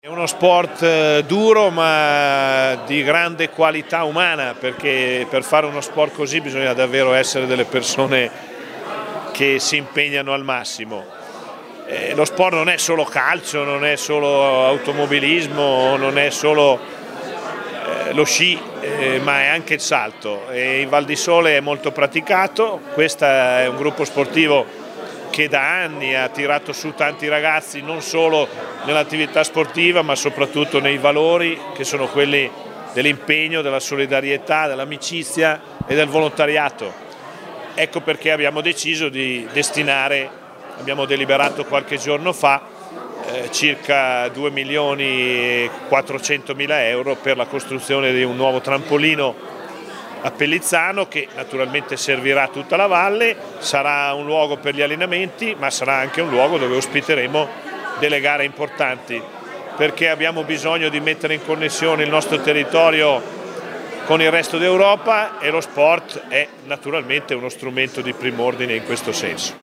Ieri a Ossana il presidente ha incontrato gli atleti del G.S. Monte Giner
7_aprile_2018_Ugo_Rossi_Ossana.mp3